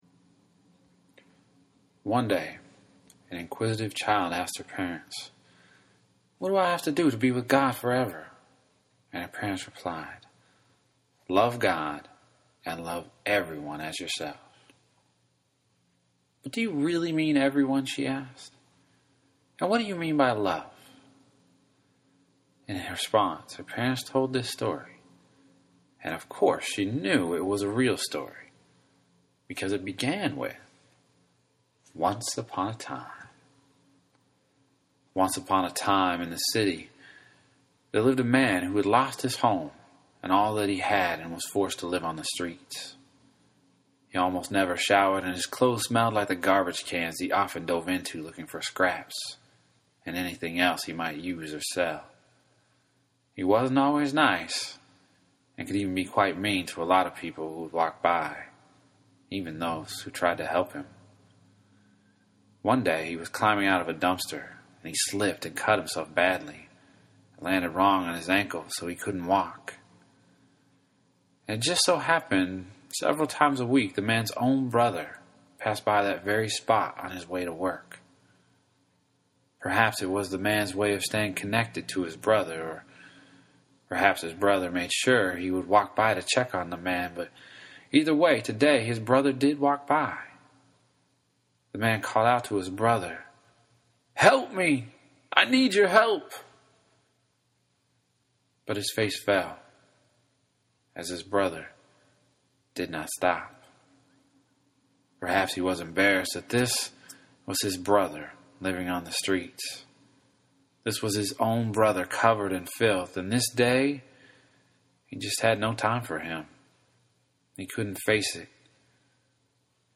the good samaritan retold (read)